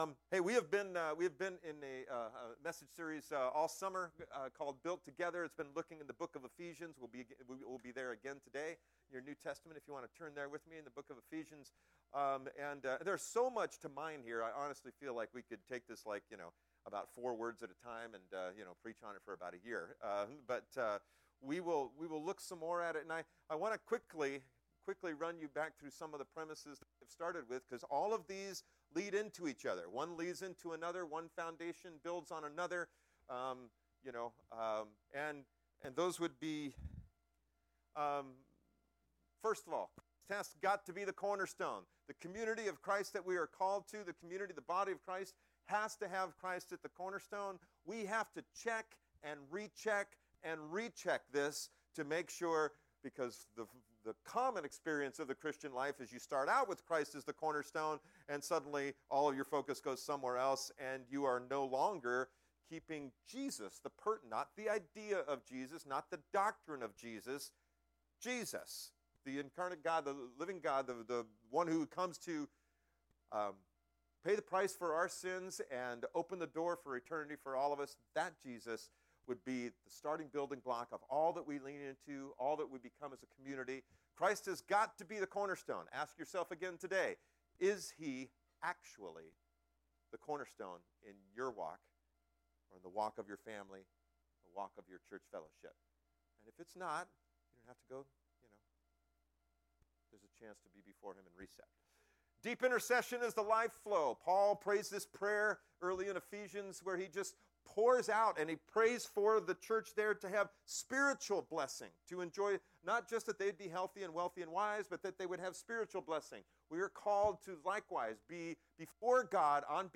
Sermons - Lighthouse Covenant Church